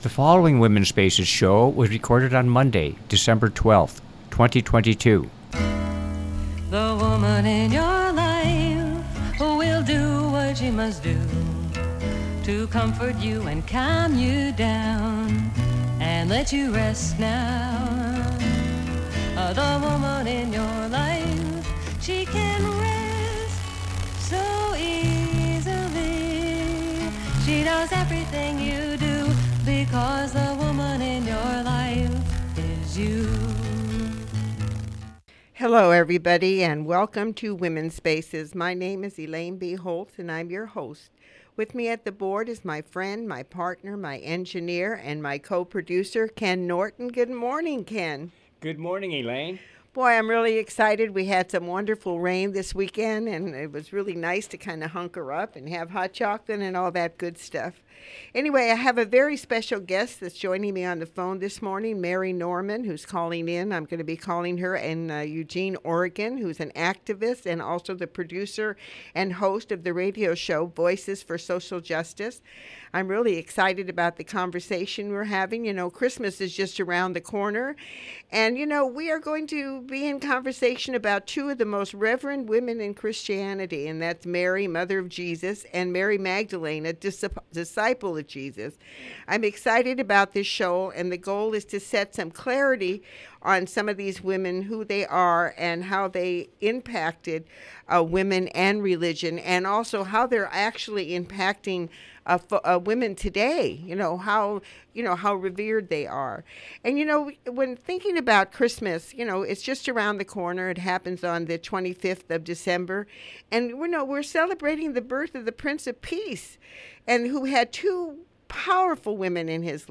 I am reading some of my poems to keep in this spirit of the Prince of Peace, who is celebrated this Christmas season, and mix in songs with this in mind.